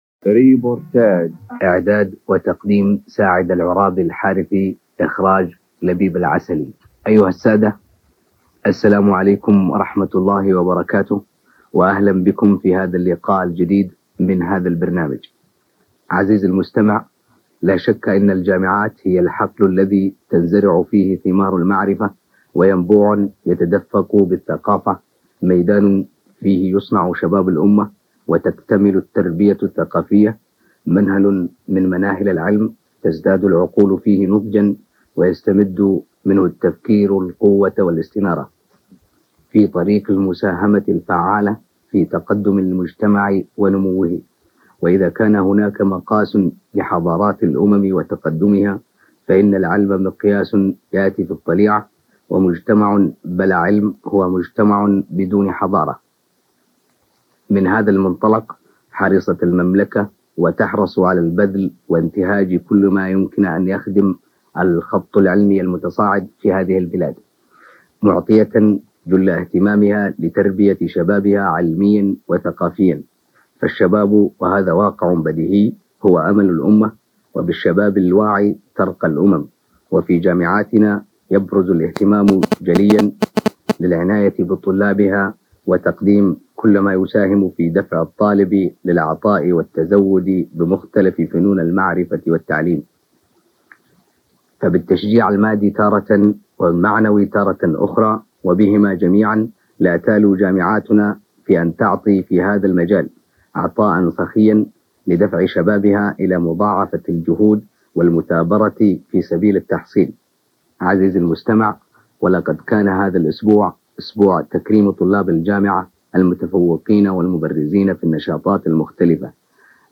ريبورتاج – عن الجامعات السعودية